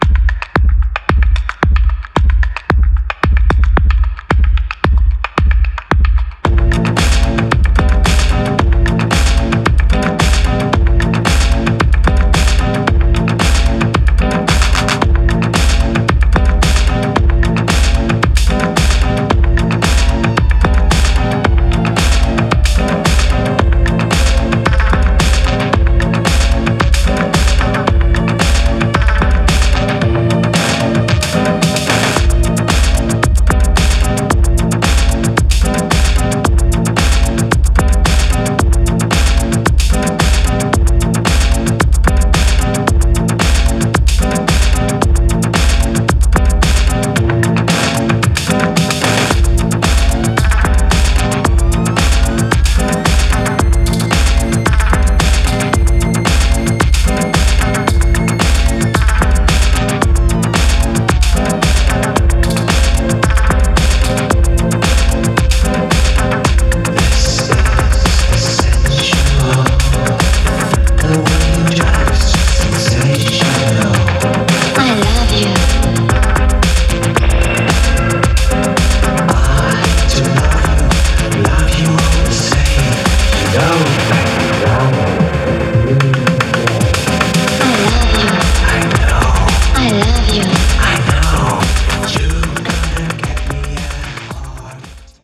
全編120BPMアンダー